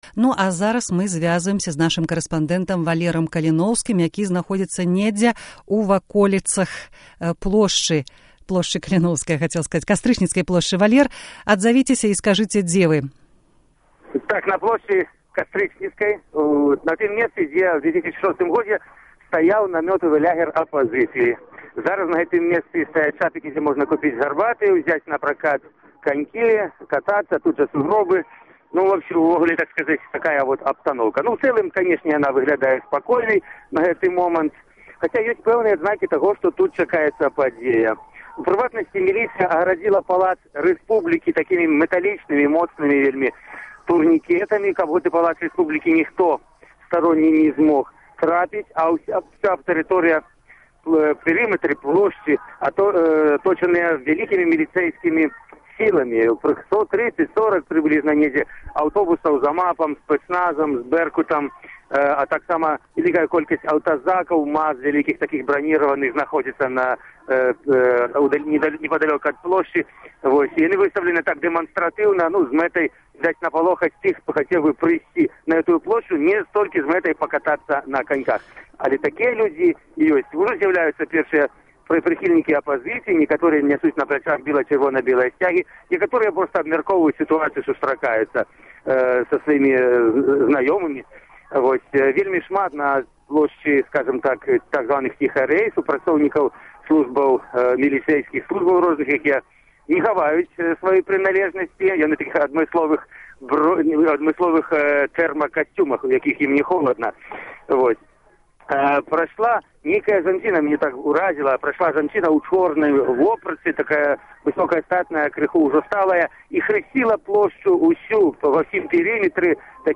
Рэпартаж